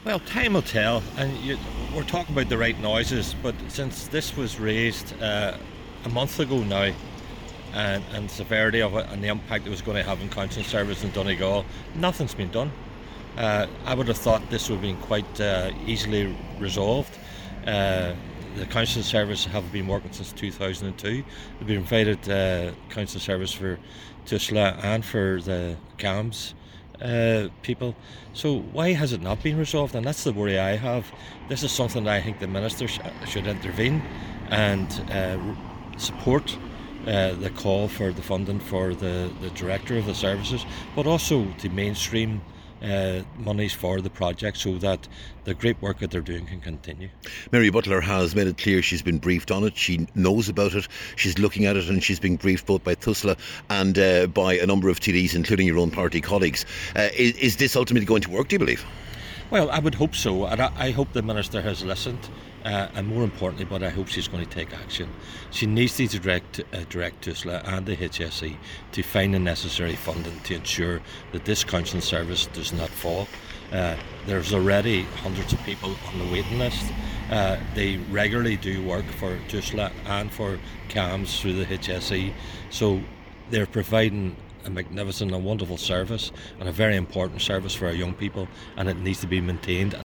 Cllr McMonagle told the meeting that this service is saving the government money by taking referrals from CAMHS, the HSE and others, and it’s concerning that while the right noises are being made, there’s still been no resolution: